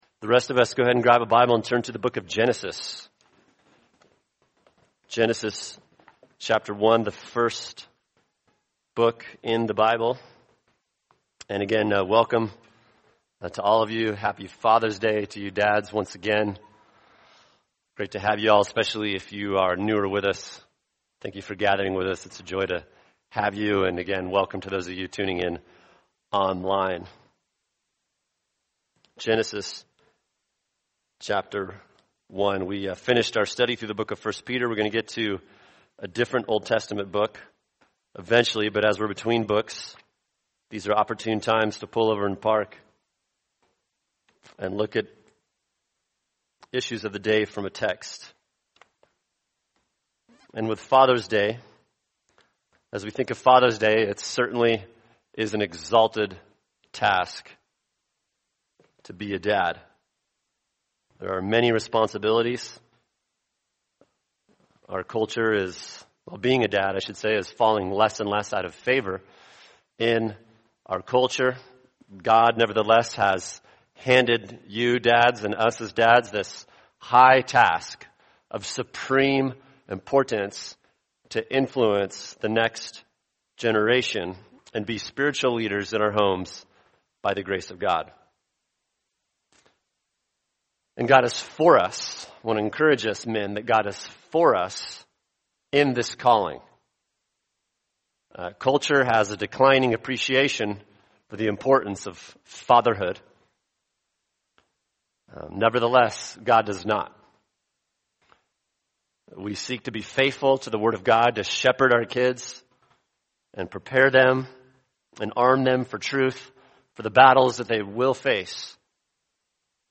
[sermon] Genesis 1-2 A Biblical Perspective of Gender and Sexuality | Cornerstone Church - Jackson Hole